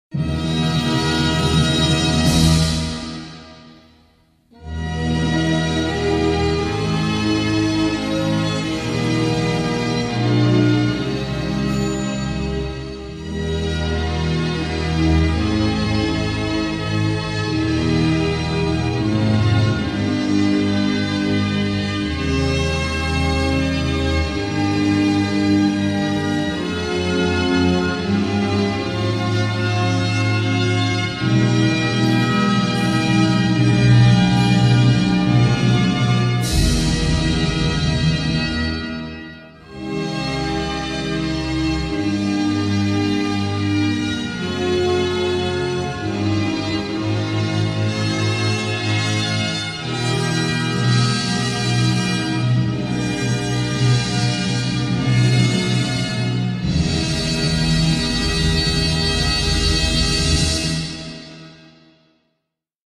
торжественную мелодию